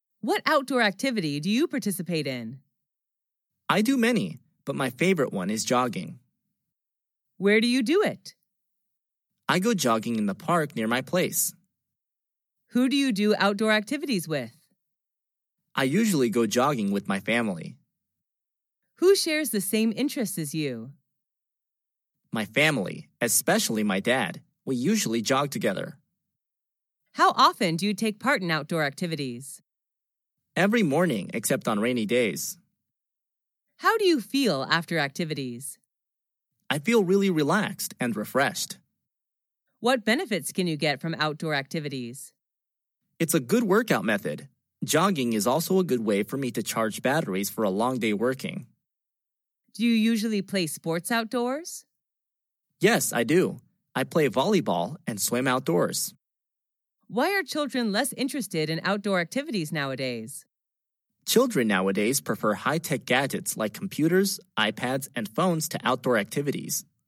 Sách nói | QA-37